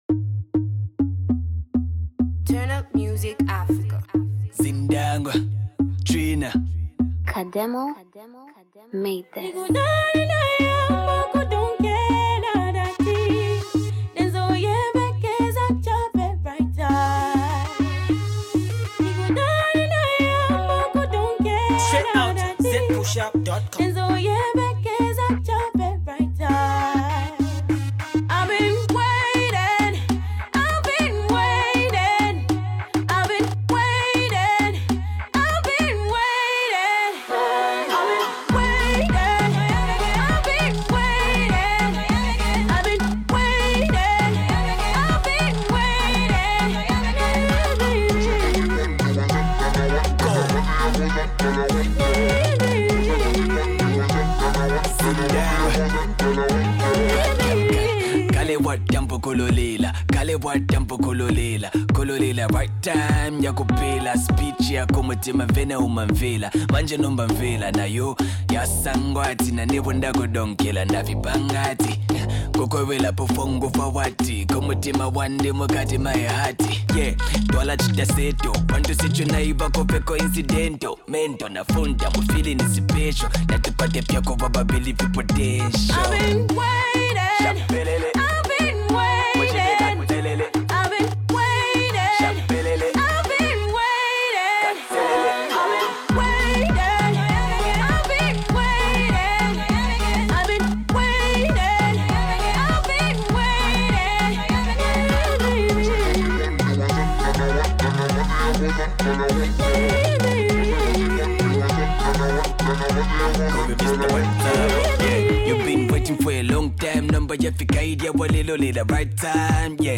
one of the most trending female act